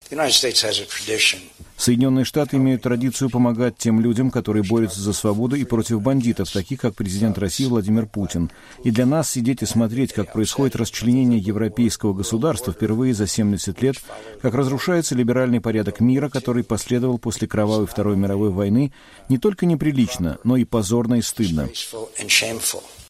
На пресс-конференции в Киеве 20 июня Маккейн сказал, что это будет "позорным и постыдным" для Запада не действовать в то время, когда президент России Владимир Путин, по мнению Маккейна, стремится расчленить Украину.